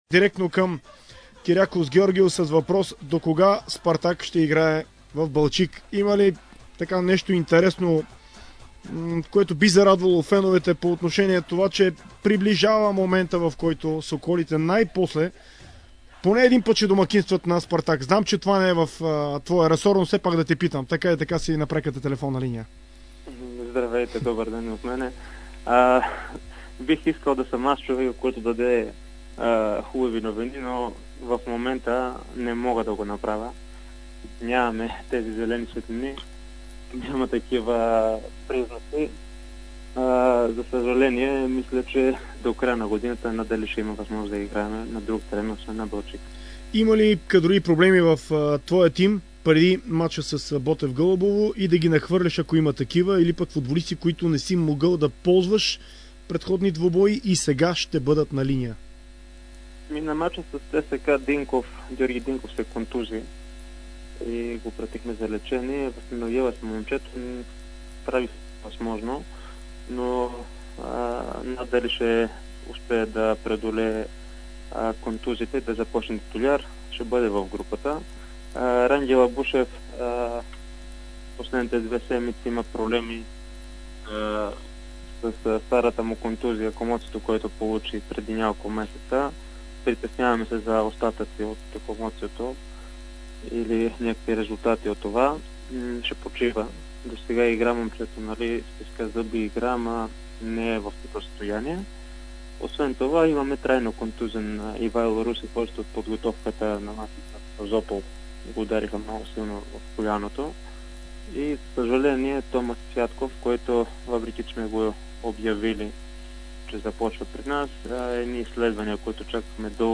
интервю за спортното предаване на Дарик Варна